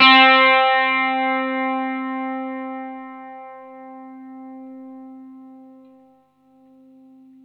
R12NOTE C +.wav